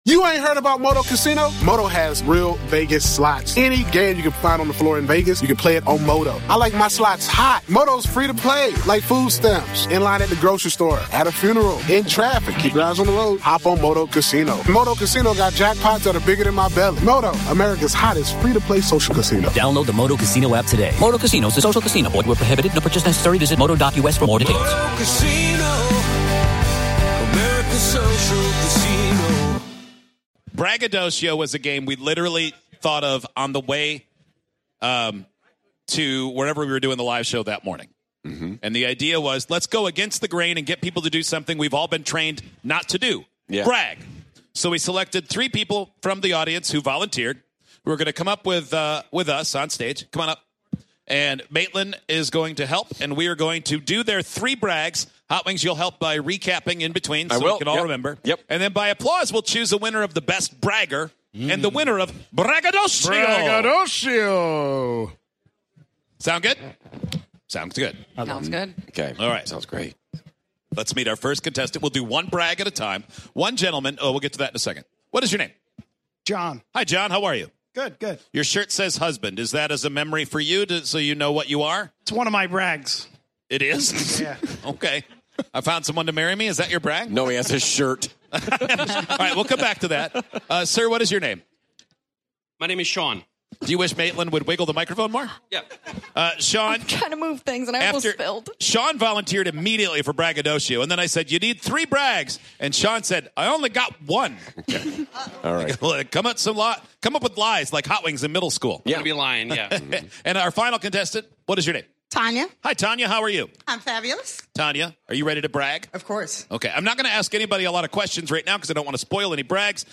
It's simple, really, three people come up on stage with three different "brags" they think are worth sharing. The audience votes and laughter ensues.